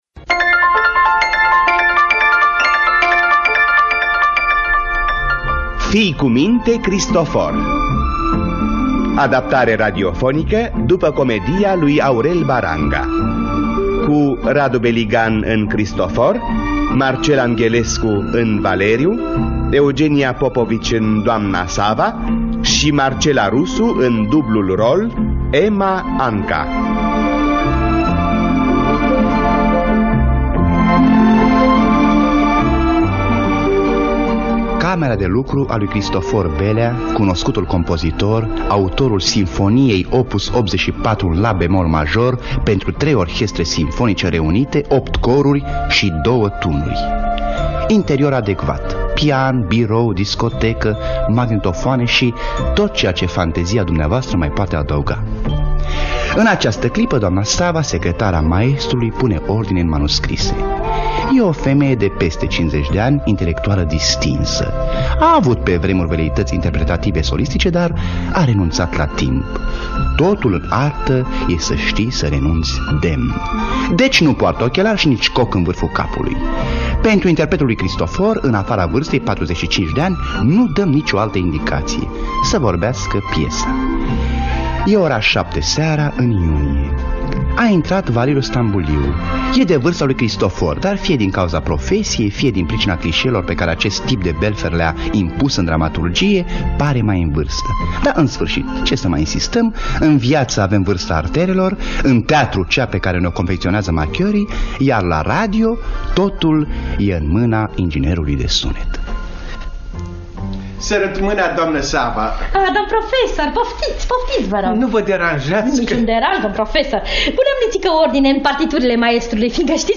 Adaptarea radiofonică de Aurel Baranga.